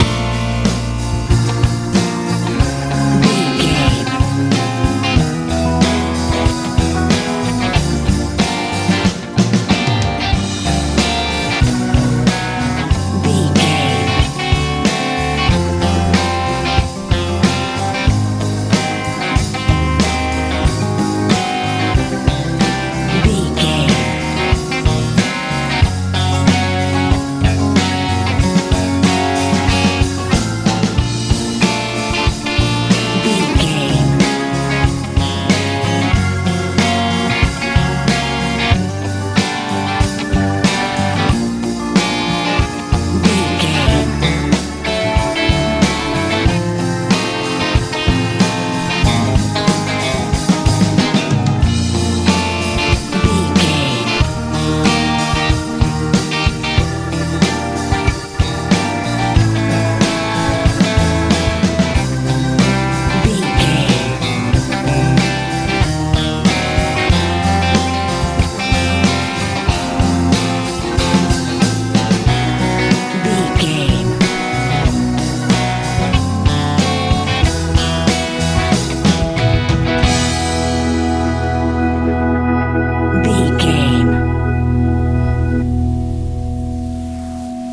southern rock feel
Ionian/Major
B♭
light
mellow
electric guitar
acoustic guitar
organ
bass guitar
drums
lively
bright